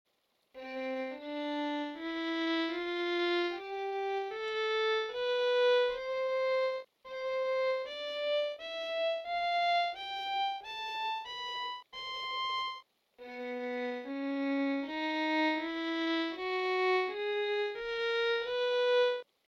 Basic_Fiddle_Sound.mp3